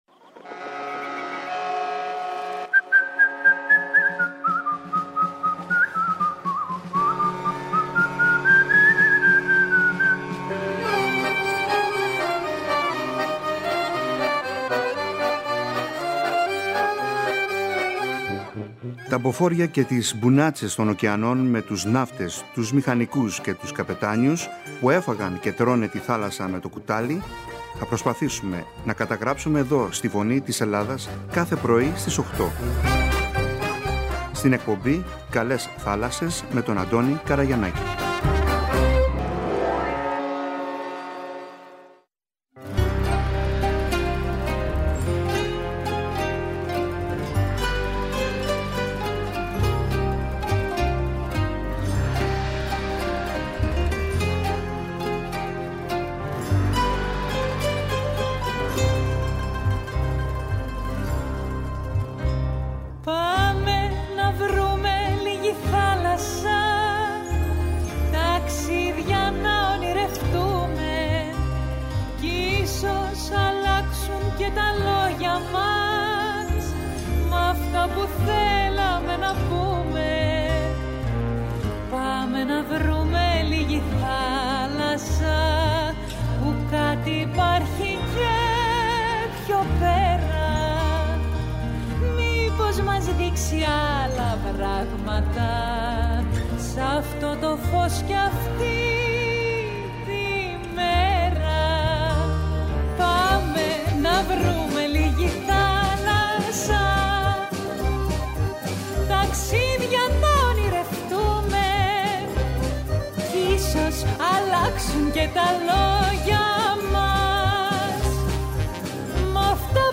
Λόγω πληθώρας ύλης (ιστορίες, βιώματα, εμπειρίες, και συναισθήματα), αλλά και κακής σύνδεσης (δεν ήταν καλό το σήμα) η εκπομπή θα έχει τουλάχιστον δύο ακόμα συνέχειες.